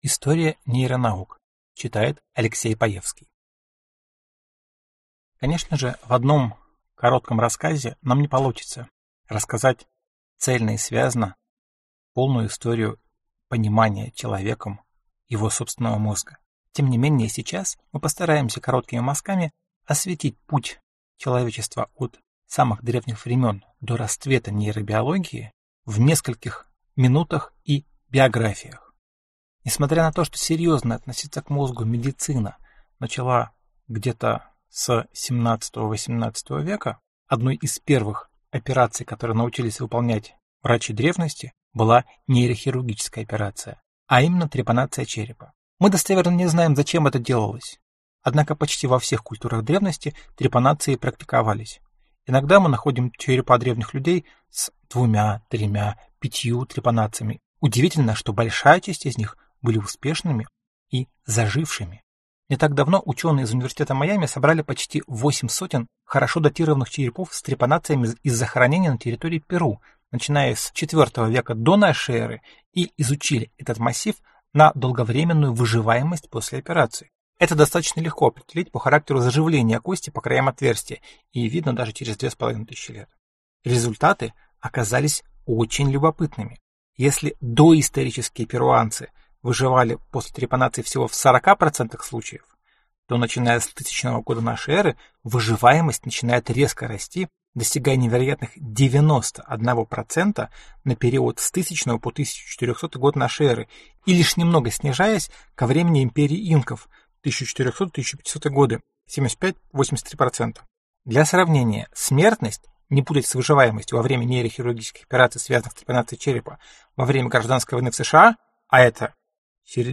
Аудиокнига Базовая нейрофизиология (Цикл из 29 лекций) | Библиотека аудиокниг